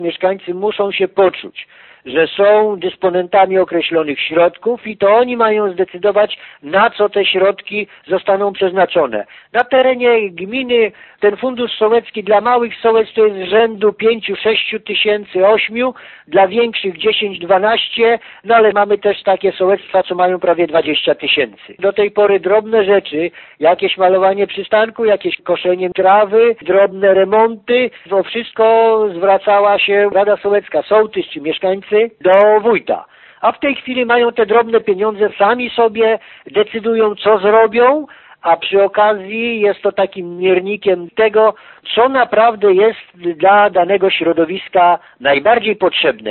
Wójt Franciszek Kwiecień chwali pomysł, bo jego zdaniem daje mieszkańcom pole do działania: